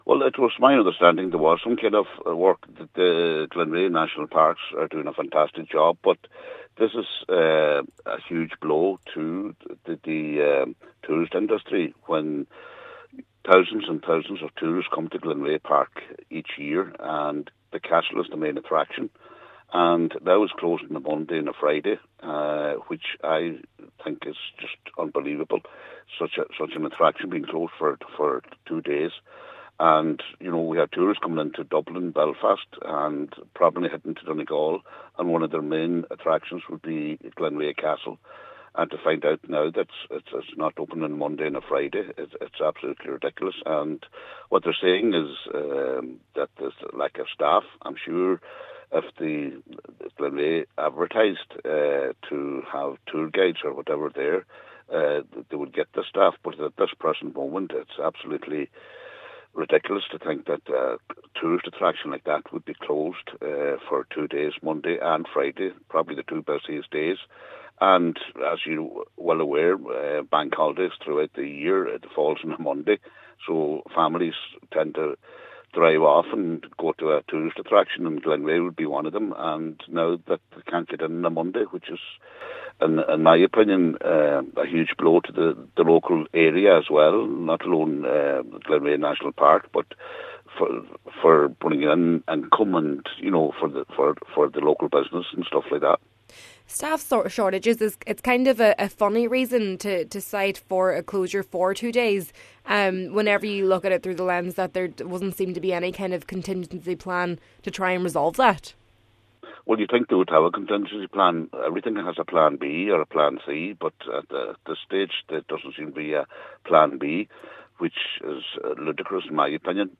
He says it’s something his fellow councillors have also raised: